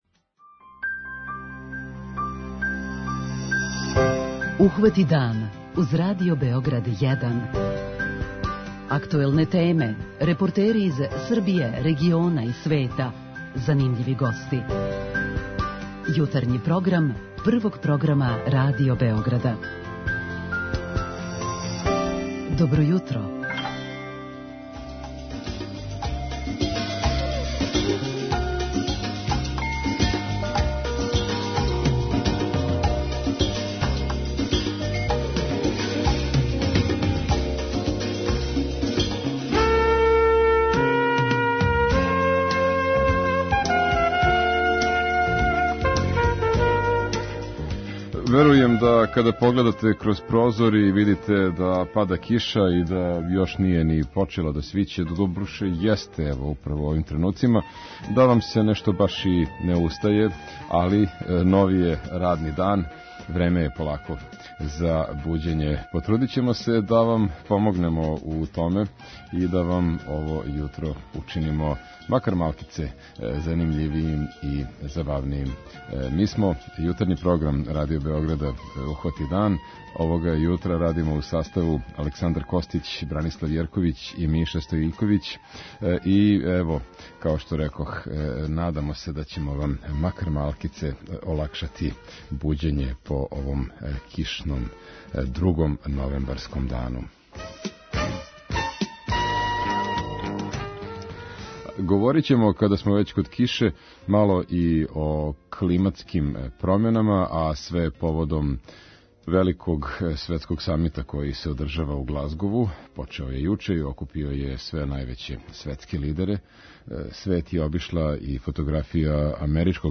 преузми : 37.79 MB Ухвати дан Autor: Група аутора Јутарњи програм Радио Београда 1!